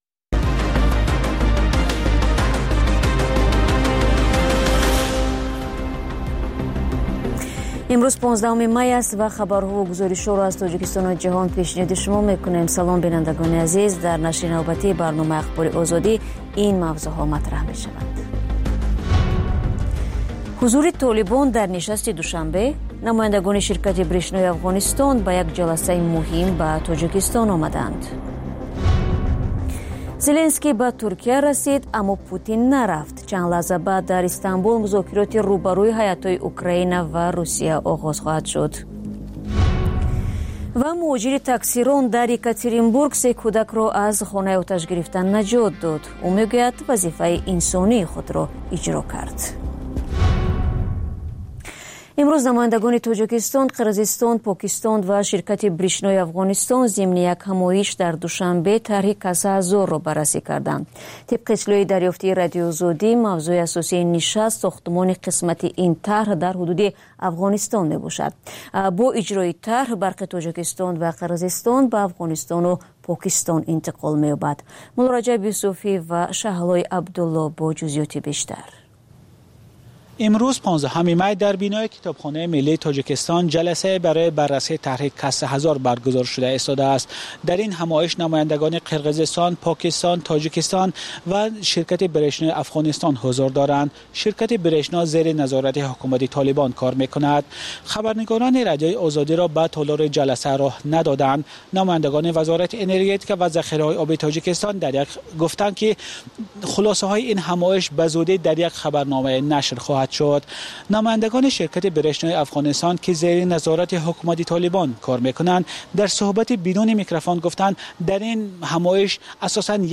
Тозатарин ахбори ҷаҳон, минтақа ва Тоҷикистон, таҳлилу баррасиҳо, мусоҳиба ва гузоришҳо аз масъалаҳои сиёсӣ, иҷтимоӣ, иқтисодӣ, фарҳангӣ ва зистмуҳитии Тоҷикистон.